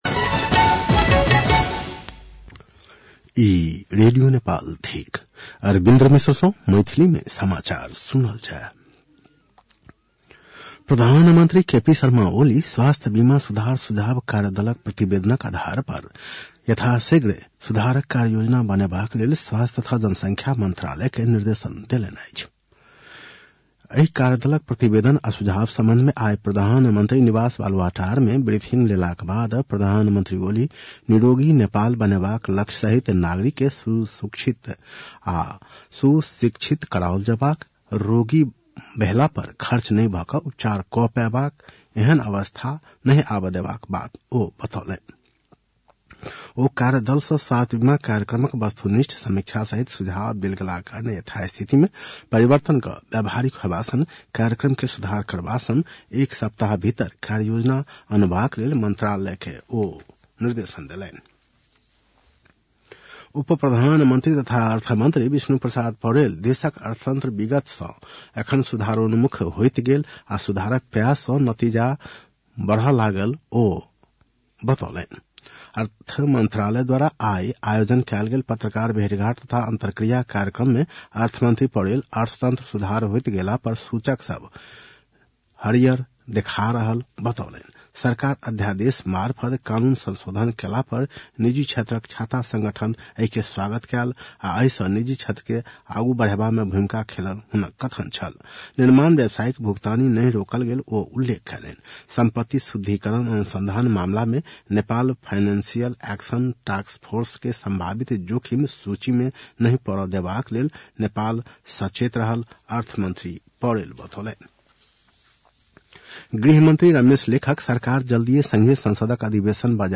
मैथिली भाषामा समाचार : ७ माघ , २०८१
Maithali-news-10-06.mp3